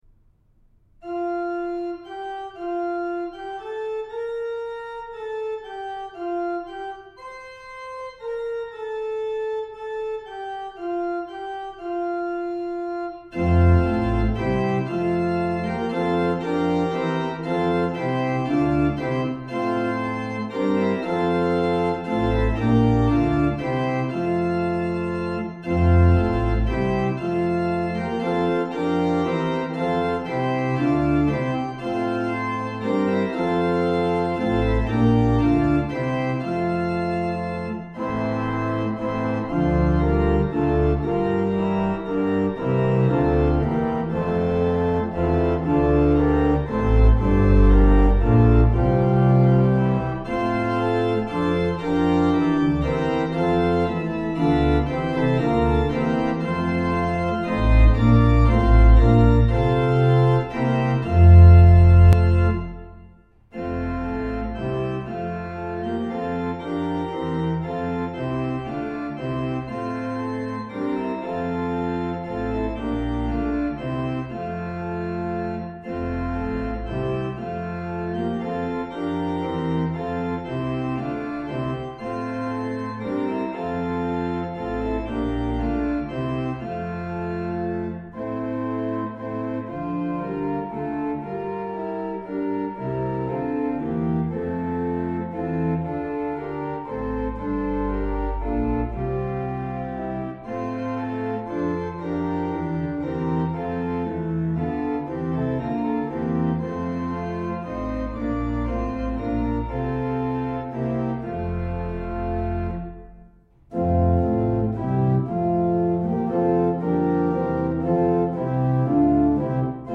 Organ: Moseley